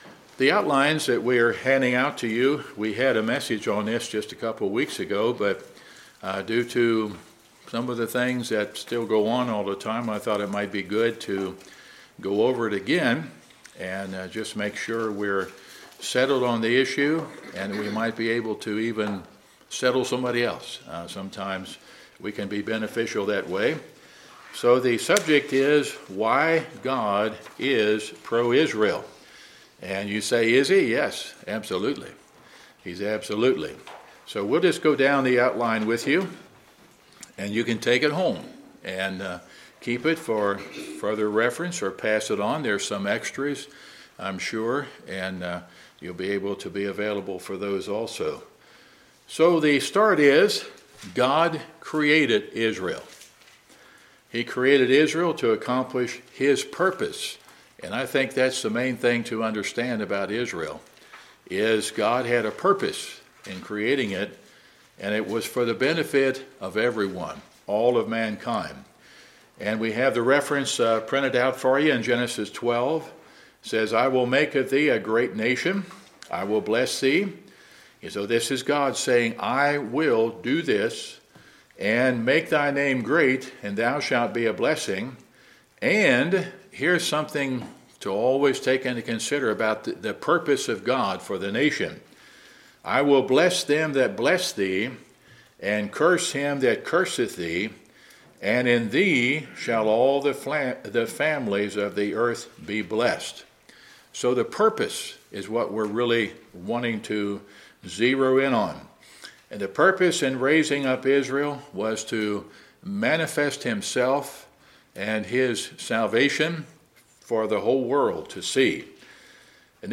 Wednesday Sermon